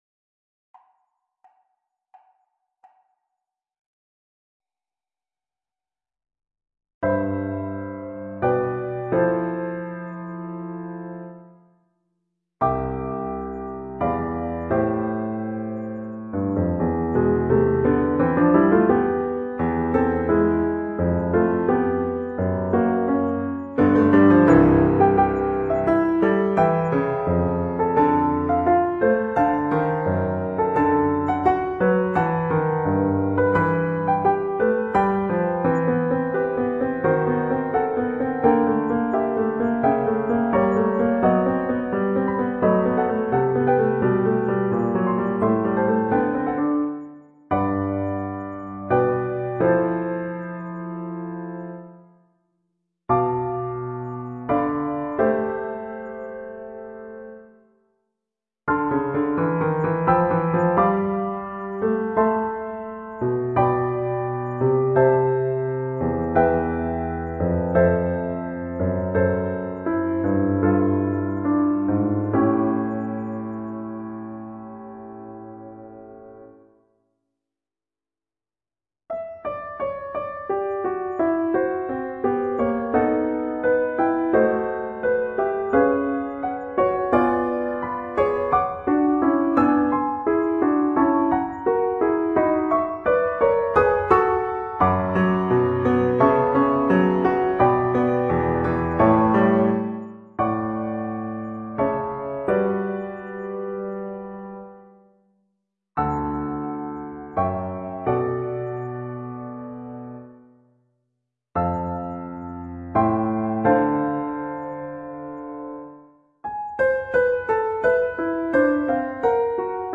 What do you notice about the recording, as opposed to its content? (Solo piano generated by Sibelius)